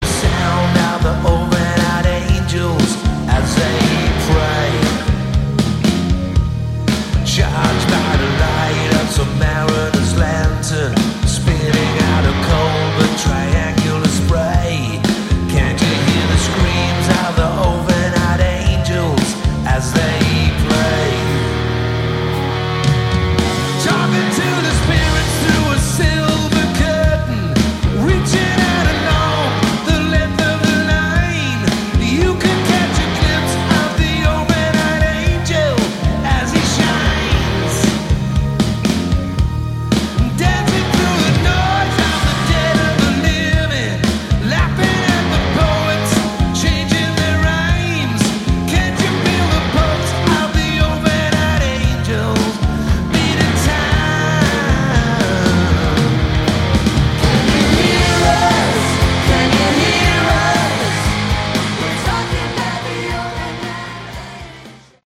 Category: Hard Rock
vocals, guitar, keyboards
drums
bass